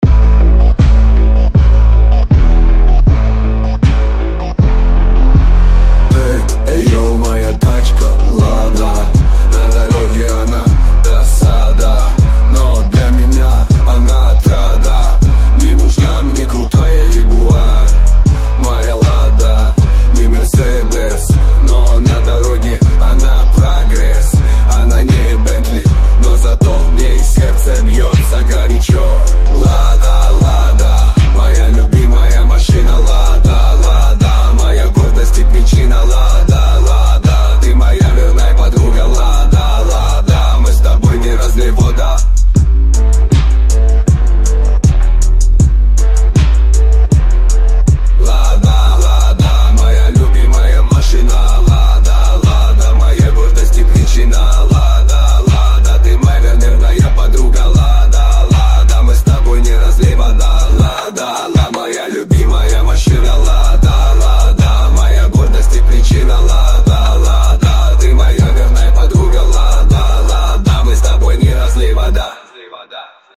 )) Слова - Алиса AI (Яндекс), Музыка и вокал - Suno AI, Продюсер - Я!
Такие биты с текстом, звук и аранж с меня ))) Порвем весь Вконтакт ))) Вложения LADA (CHIRP).mp3 LADA (CHIRP).mp3 2,8 MB · Просмотры: 770